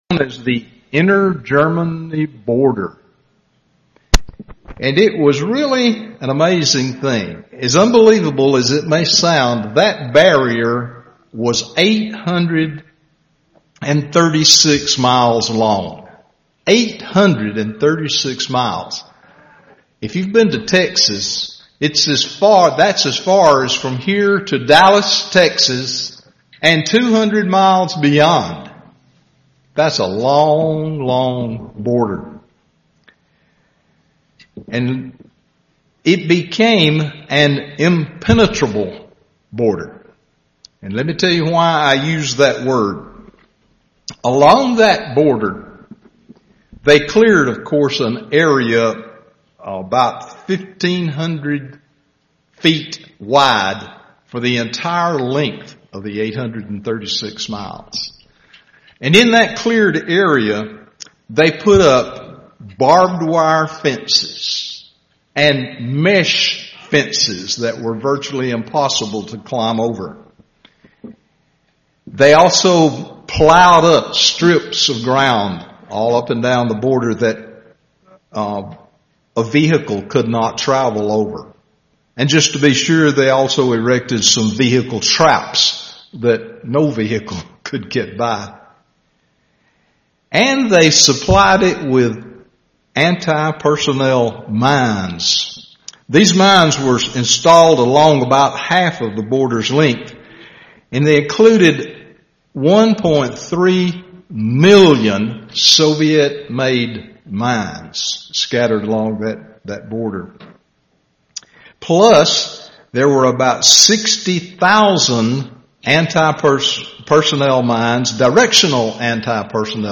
Sermons
Given in Birmingham, AL